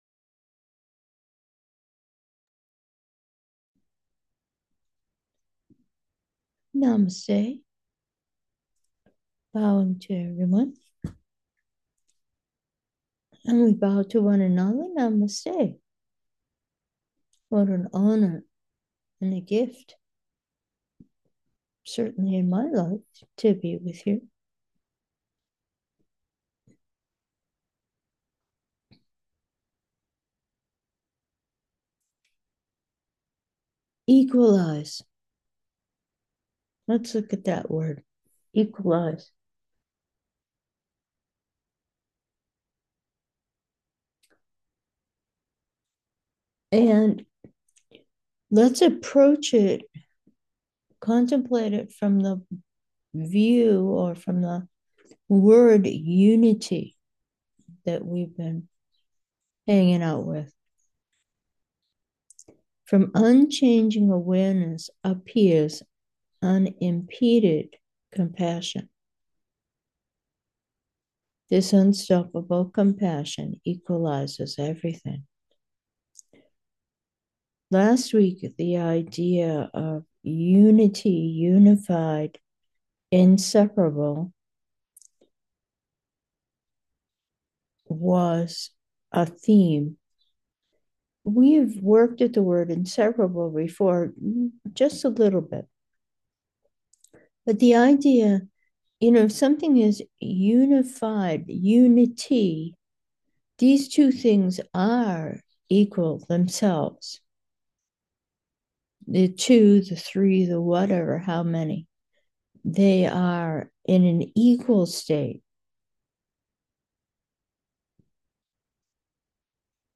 Meditation: unity, equality